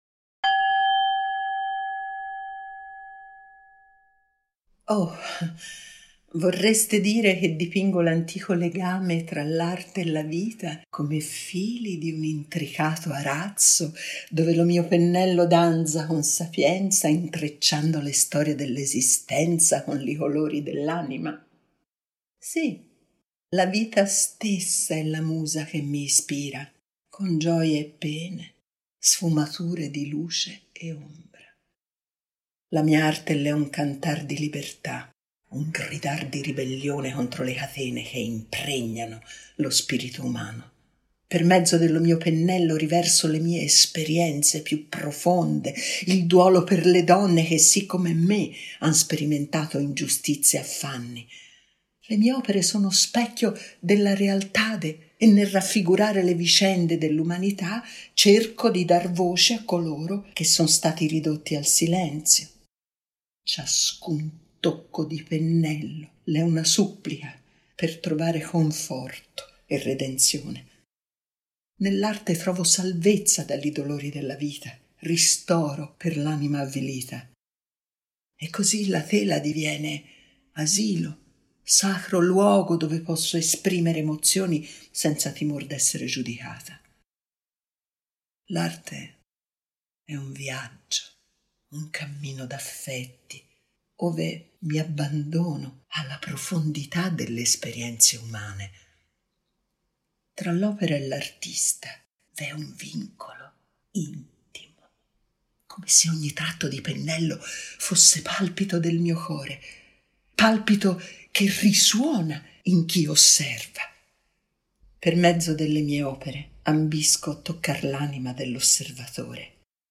Artemisia Gentileschi (interpretata da Pamela Villoresi).